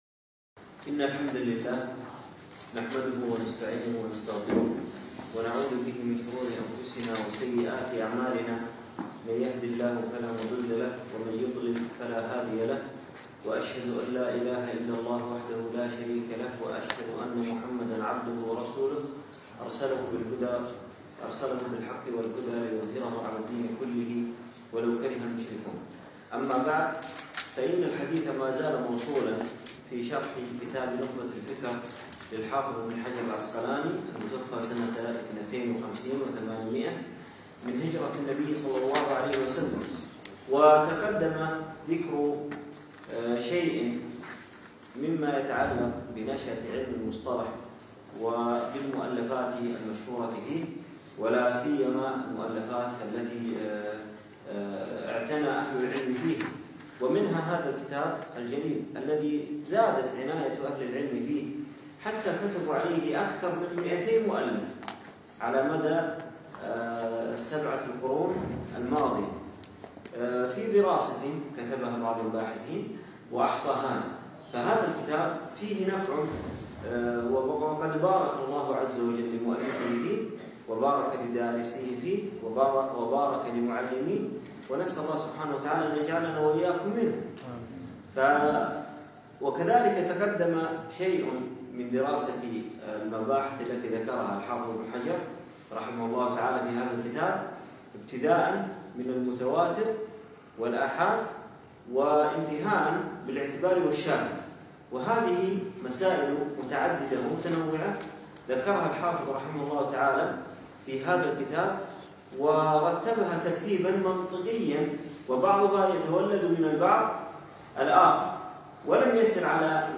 يوم الجمعة 6 جمادى أول 1438 الموافق 3 2 2017 في مسجد زين العابدين سعد ال عبدالله